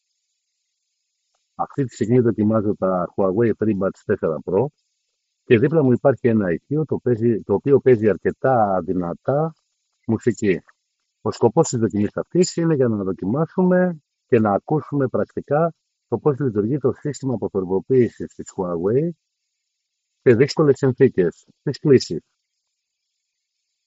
Δοκίμασα την αποτελεσματικότητα του συστήματος κάνοντας εγγραφή χρησιμοποιώντας το Adobe Audition έχοντας ένα μικρό ηχείο ακριβώς δίπλα μου το οποίο έπαιζε δυνατά μουσική (για την ιστορία έπαιζε το Afterglow of Ragnarok του Bruce Dickinson). Το αποτέλεσμα είναι κάτι παραπάνω από εντυπωσιακό καθώς η μουσική απλά έχει εξαφανιστεί.